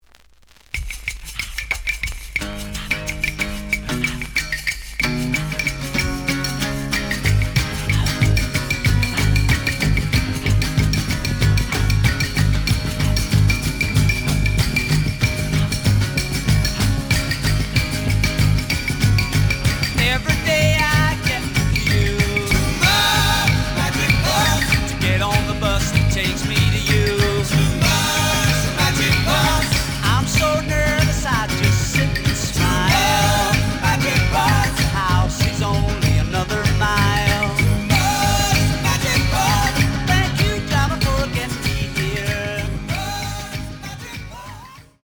The audio sample is recorded from the actual item.
●Format: 7 inch
●Genre: Rock / Pop
Slight edge warp.